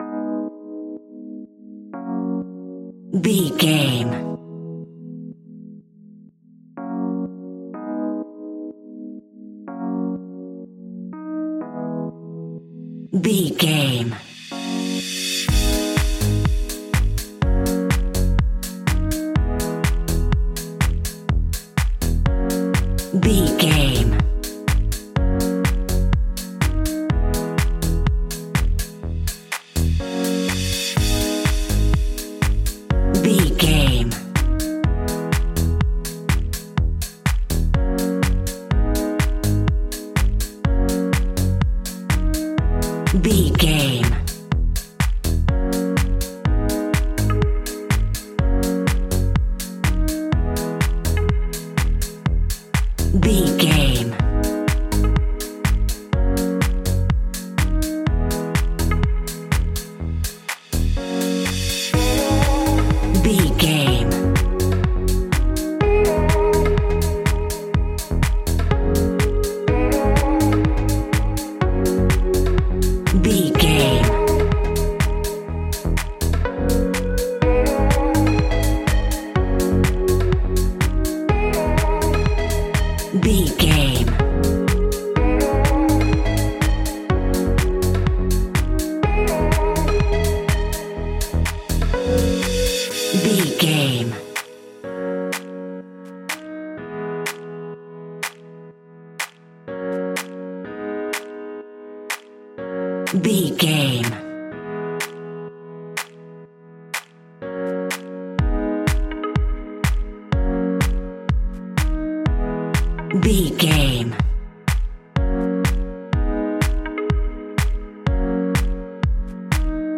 Aeolian/Minor
G#
groovy
uplifting
futuristic
energetic
drum machine
synthesiser
piano
electro house
synth leads
synth bass